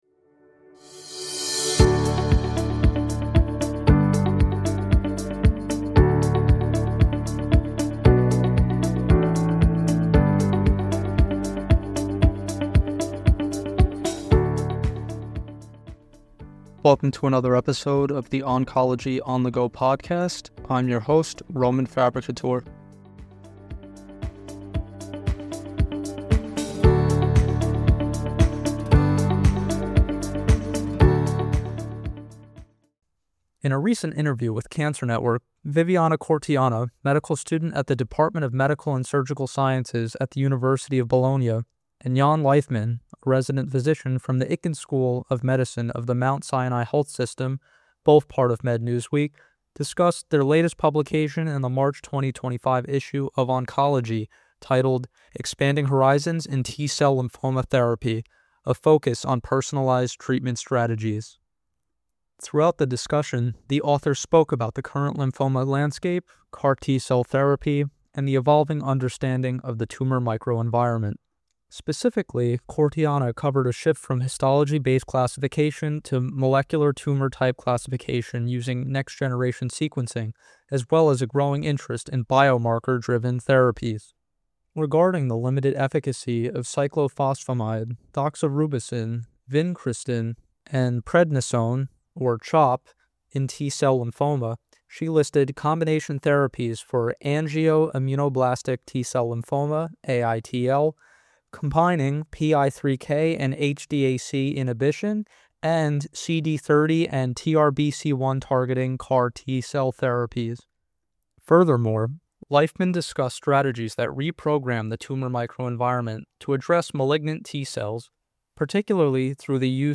In a conversation with CancerNetwork®